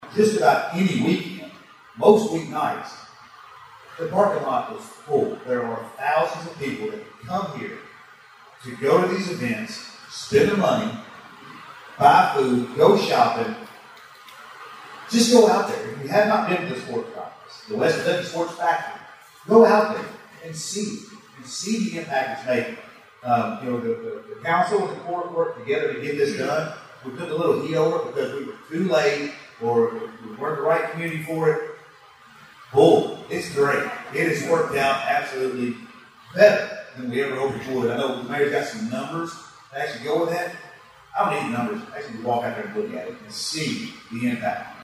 The Hopkins County Humane Society’s shelter and the economic impact of the Kentucky Sports Factory were among topics discussed last week at the State of the Cities and County event, hosted by the Hopkins County Regional Chamber of Commerce.